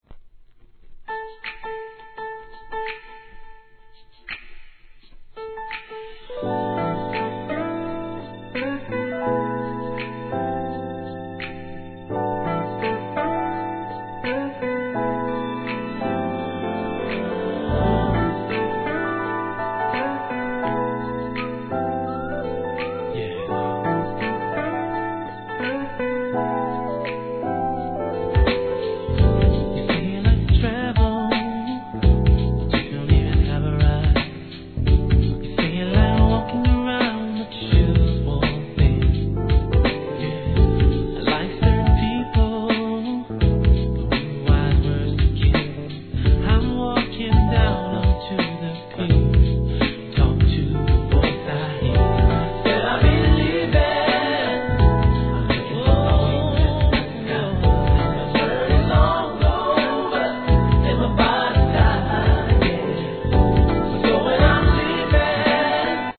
HIP HOP/R&B
心地よく清々しいトラックに、清涼感たっぷりのヴォーカルが最高に気持ちよし。